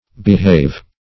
Behave \Be*have"\, v. i.